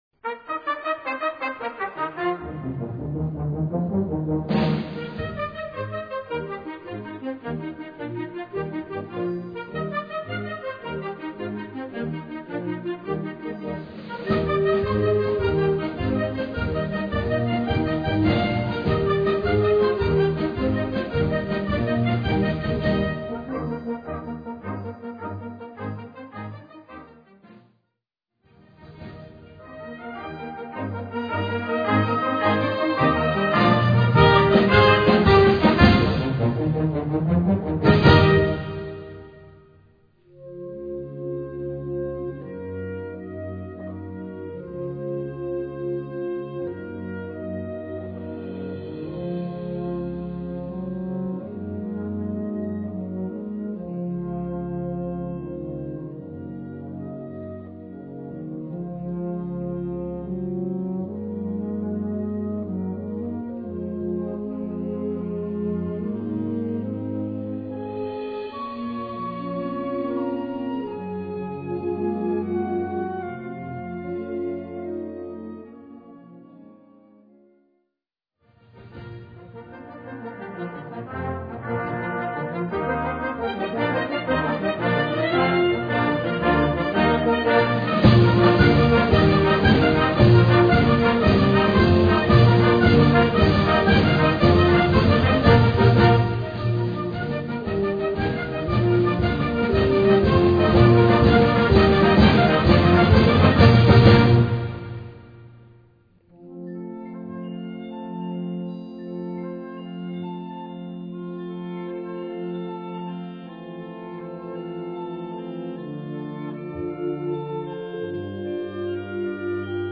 Categorie Harmonie/Fanfare/Brass-orkest
Subcategorie Potpourri, medley, selectie : concert
Bezetting Ha (harmonieorkest)